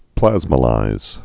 (plăzmə-līz)